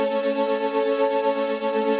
SaS_MovingPad01_120-A.wav